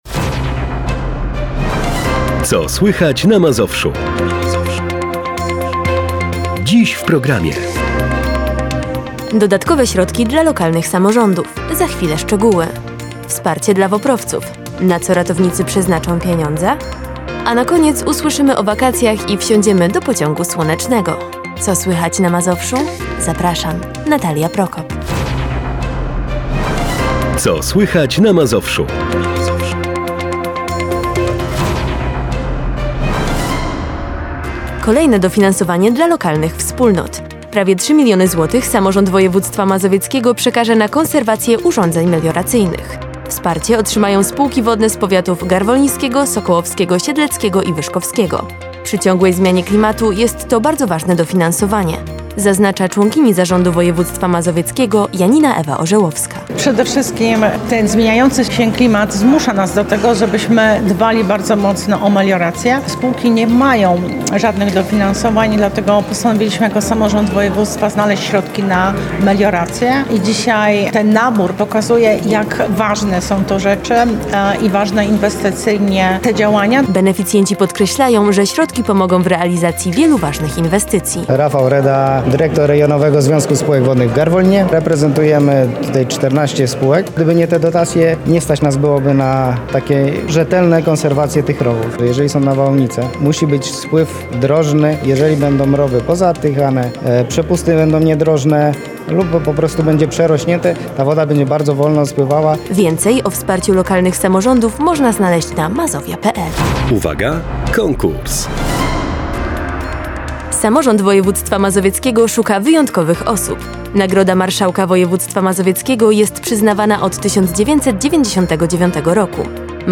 3 STUDIA PLENEROWE W 3 DNI - Radio Bogoria
W ostatni weekend tak dopisało imprez, że aż grzały się nasze mikrofony:) Przez trzy dni, wystawialiśmy nasze studio plenerowe. W piątek na Stawach Walczeskiego braliśmy udział w Dniu Policji, w sobotę szybkie przenosiny do Parku Skarbków, gdzie odbyło się Motoserce, a niedzielne popołudnie spędziliśmy na obsłudze Festiwalu Mundurowych Orkiestr Dętych.